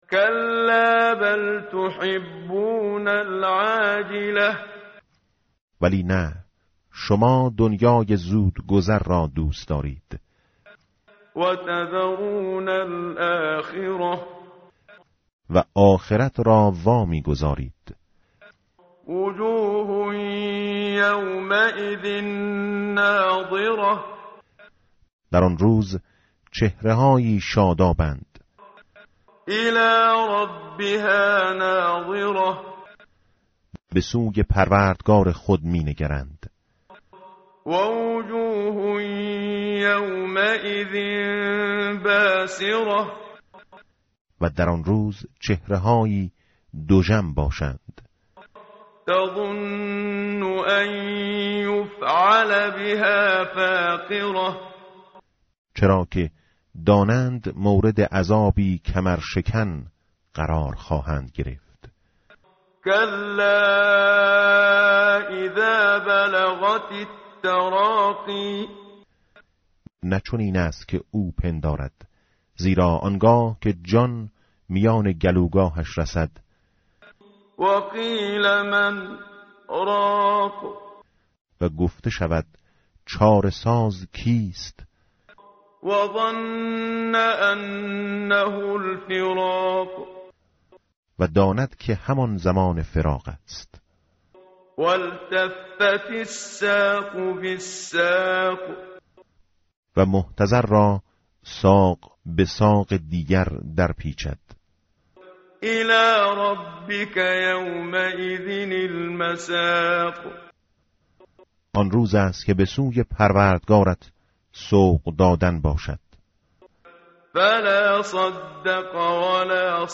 متن قرآن همراه باتلاوت قرآن و ترجمه
tartil_menshavi va tarjome_Page_578.mp3